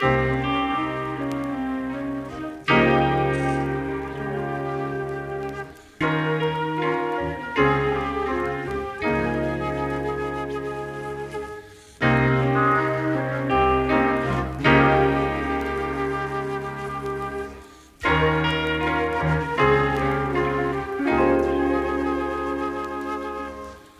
REAL DEEP 160 BPM - FUSION.wav